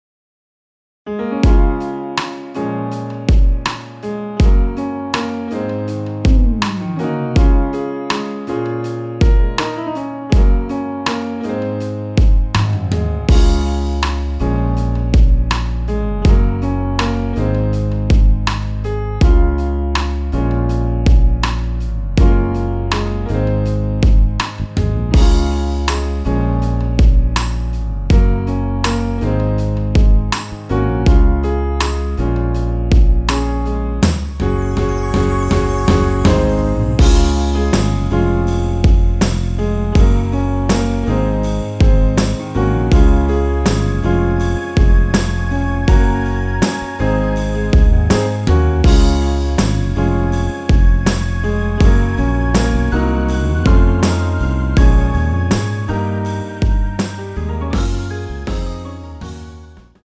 Db
앞부분30초, 뒷부분30초씩 편집해서 올려 드리고 있습니다.